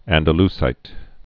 (ăndə-lsīt)